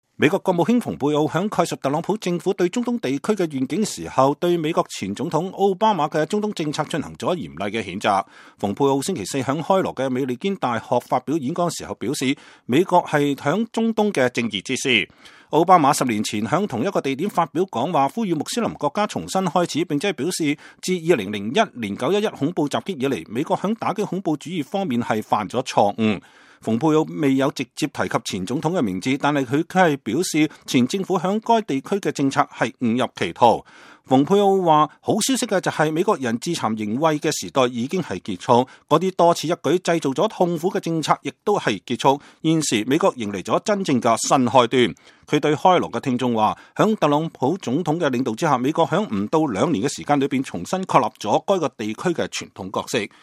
蓬佩奧在開羅美利堅大學發表講話。